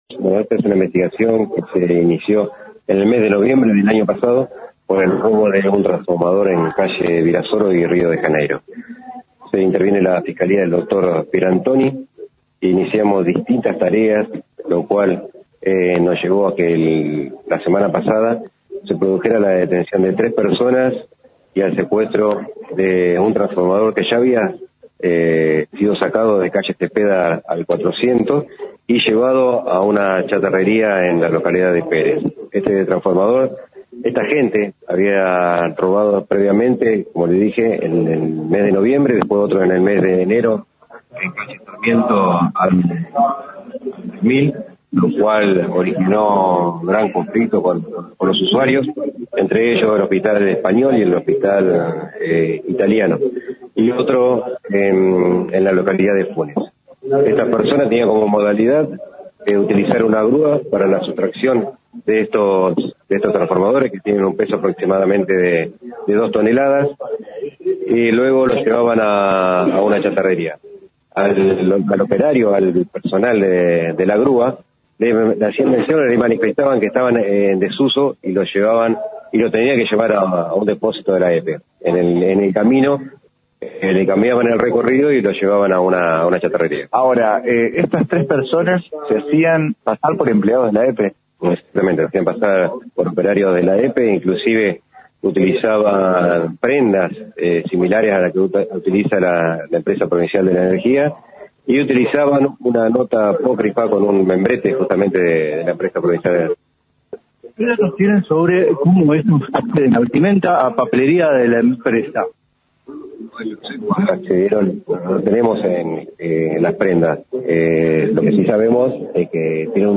El director provincial de la PDI, Natalio Marciani, habló con el móvil de LT3 y se refirió al procedimiento que realizaron tras una larga investigación, que derivó en la detención de tres personas, las cuales se hacían pasar por empleados de la Empresa Provincial de la Energía y con una grúa, robaban transformadores de la empresa en Rosario y la región.